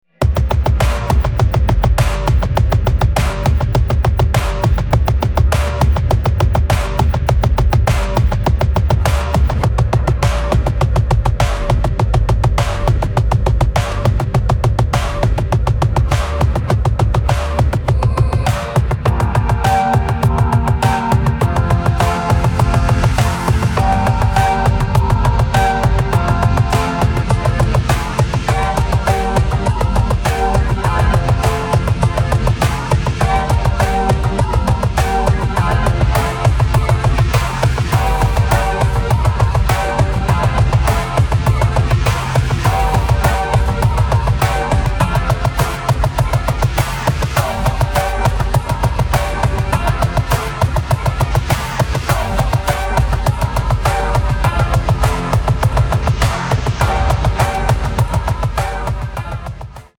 制作一首用于结尾致谢的歌曲，主要用钢琴演奏的纯音乐